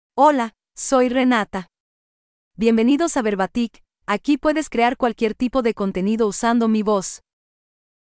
FemaleSpanish (Mexico)
Renata — Female Spanish AI voice
Renata is a female AI voice for Spanish (Mexico).
Voice sample
Renata delivers clear pronunciation with authentic Mexico Spanish intonation, making your content sound professionally produced.